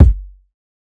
kits/OZ/Kicks/K_PickUp.wav at main
K_PickUp.wav